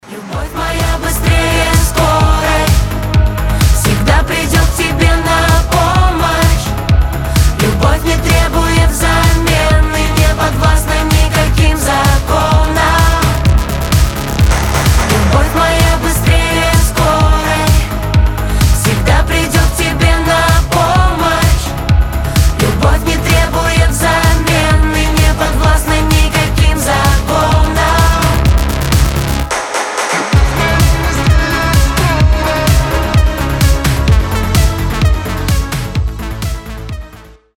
• Качество: 320, Stereo
Synth Pop
сильные
Electropop